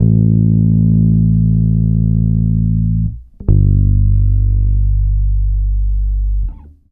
C BASS 1.wav